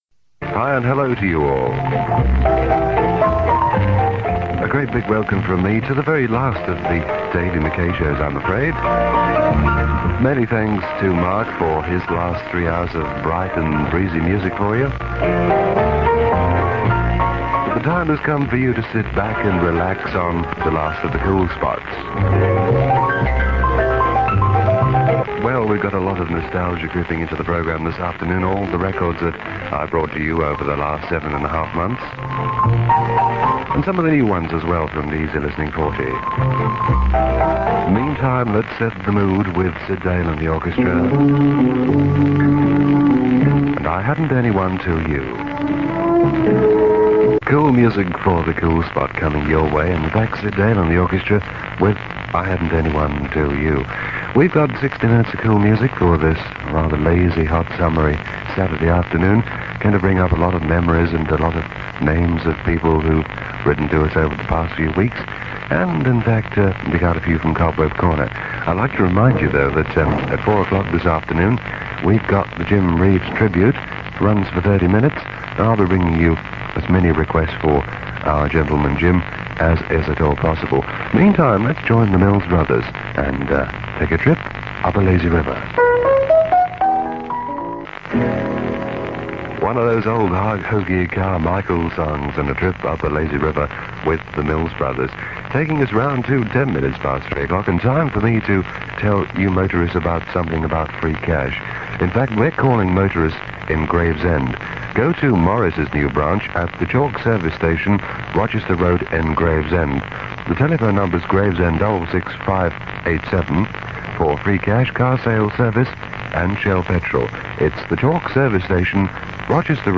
Apologies for the interference.